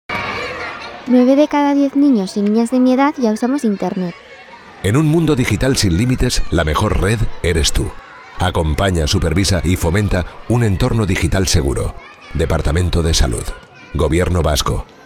Cuña de radio (castellano)
Audios institucionales breves para su emisión